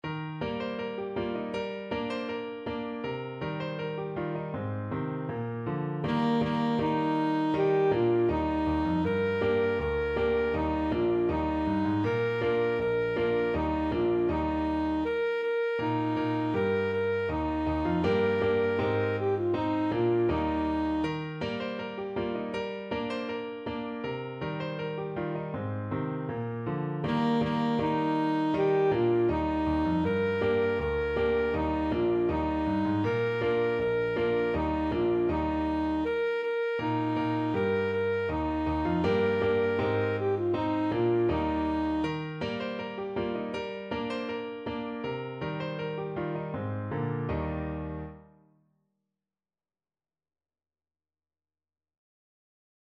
Alto Saxophone
2/4 (View more 2/4 Music)
Steadily =c.80
Bb4-Bb5
Traditional (View more Traditional Saxophone Music)
Chinese